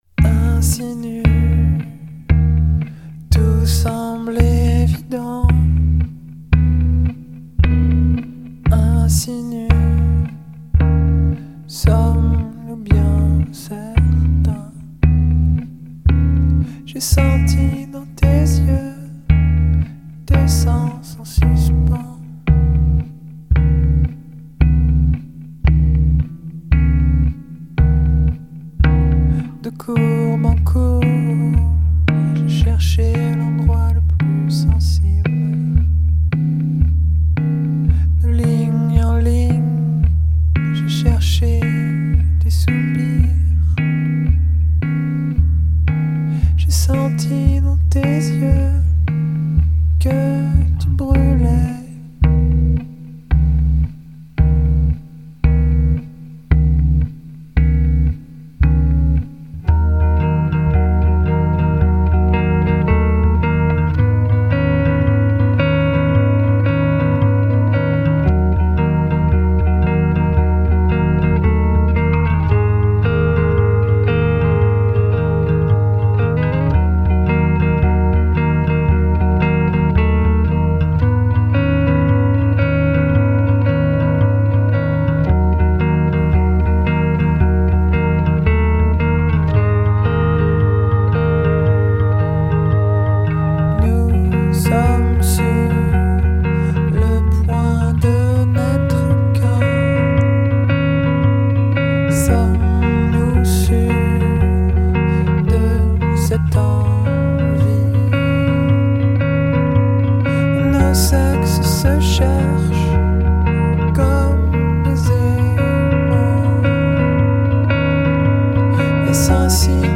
Rock, pop
Pièce musicale inédite